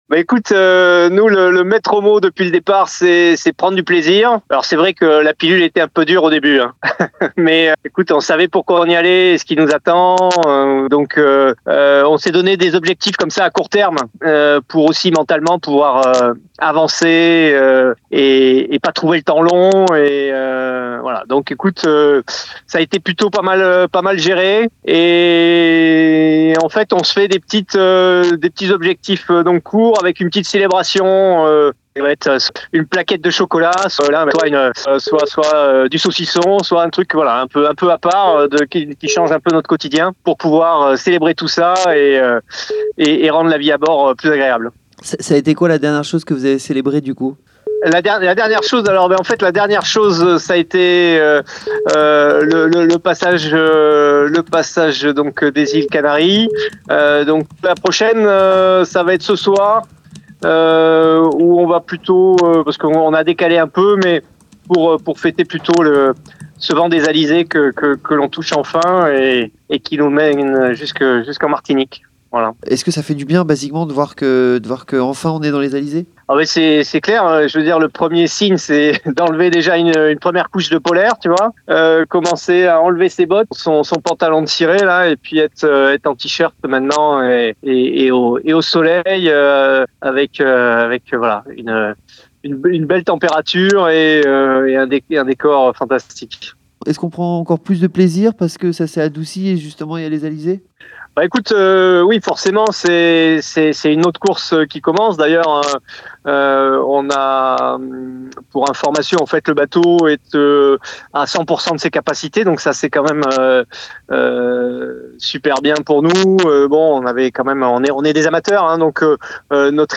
Contact radio pendant la course
Vacation audio 08 novembre 2025